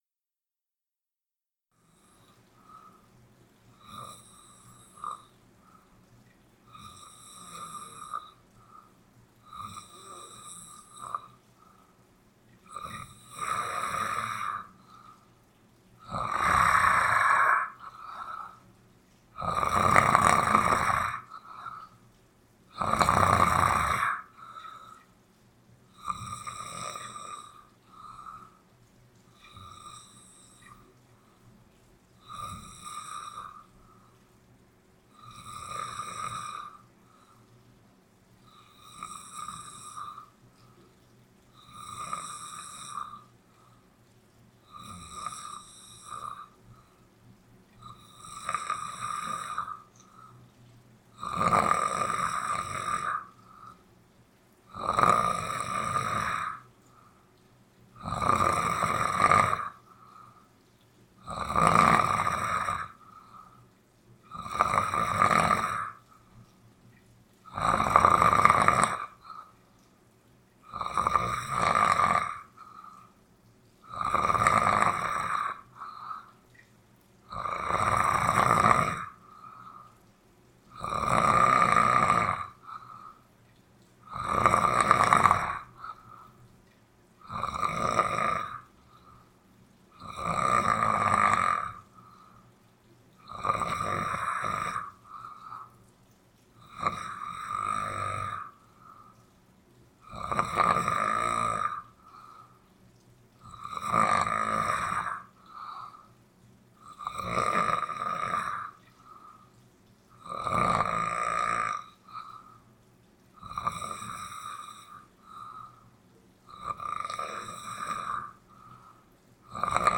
10 minutos de roncos e chuva (poema material do poeta sonhante) | MATLIT: Materialities of Literature